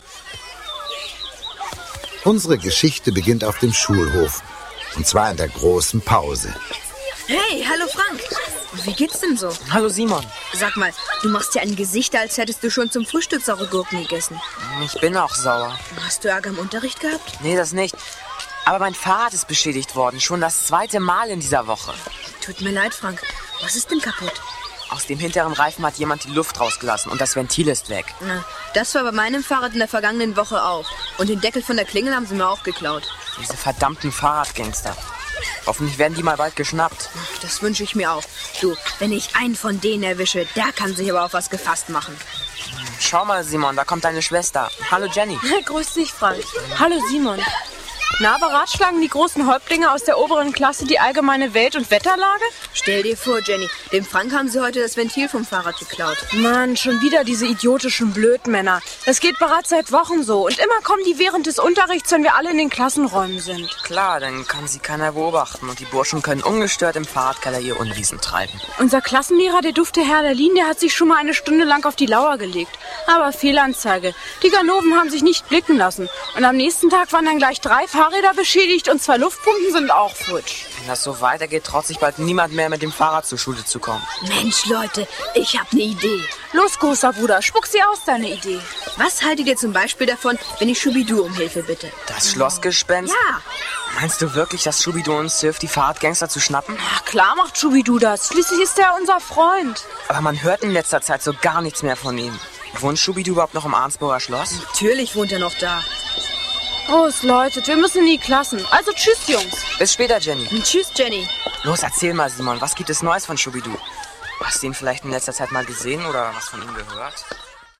Ravensburger Folge 3: Schubiduund ..uh - spukt pfiffig in der Schule ✔ tiptoi® Hörbuch ab 4 Jahren ✔ Jetzt online herunterladen!